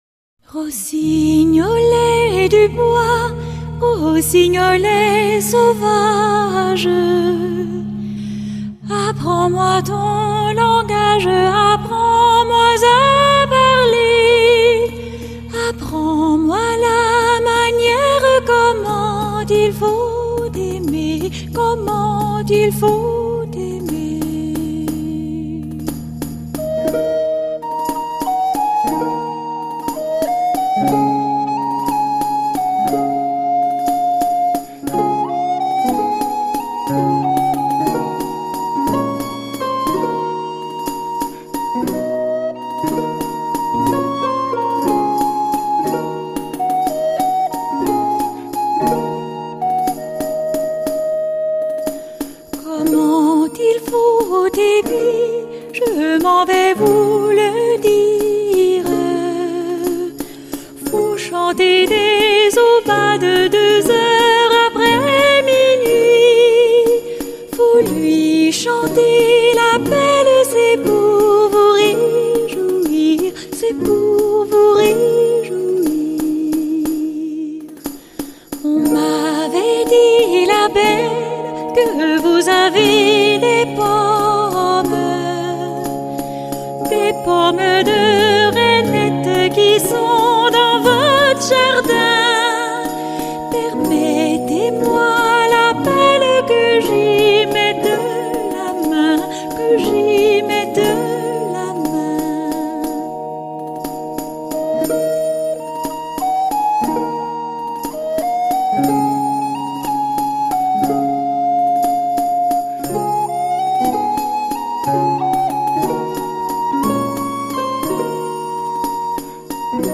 回忆的声音应该唱起来是平和的，再惊涛骇浪都是淡淡的一笔。